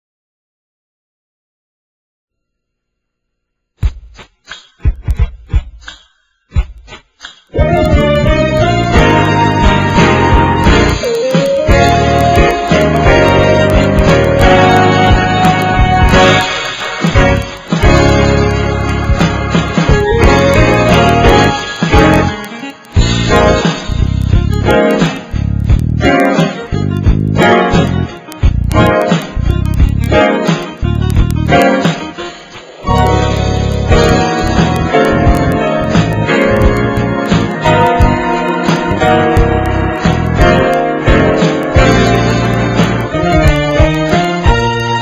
NOTE: Background Tracks 1 Thru 10